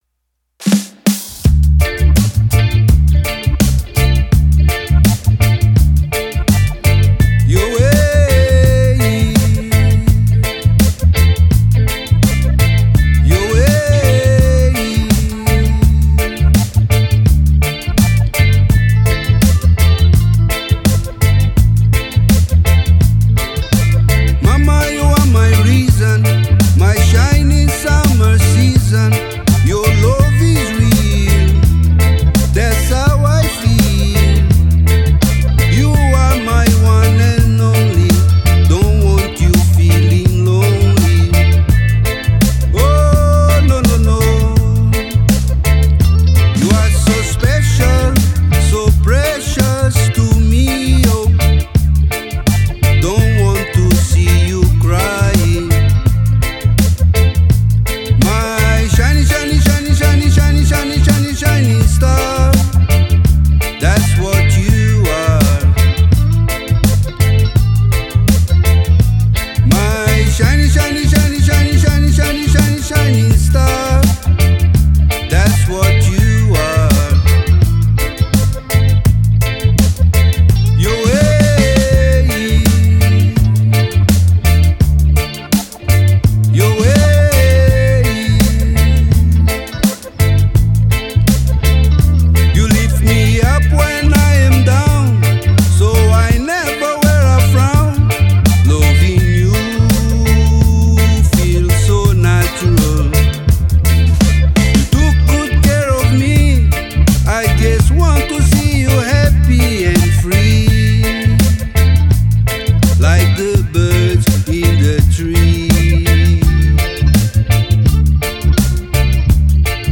studio single